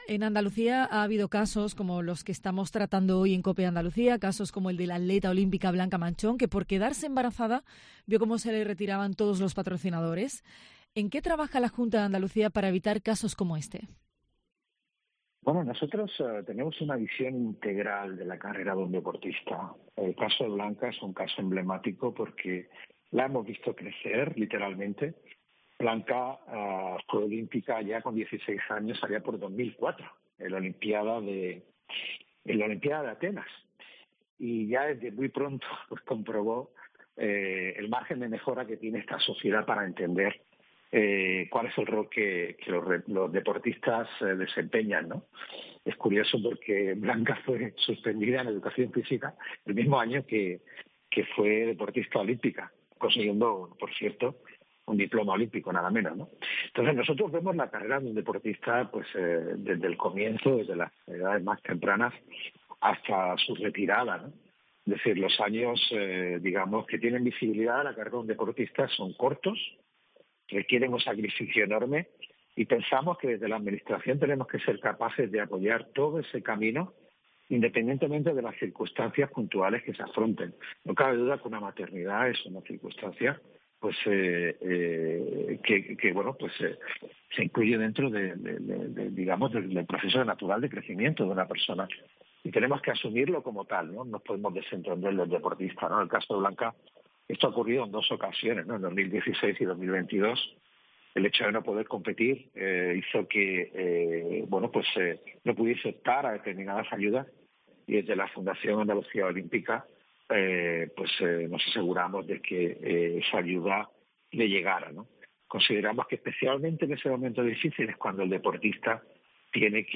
José María Arrabal, Secretario General de Deportes de la Junta de Andalucía , ha atendido a los micrófonos de COPE Andalucía .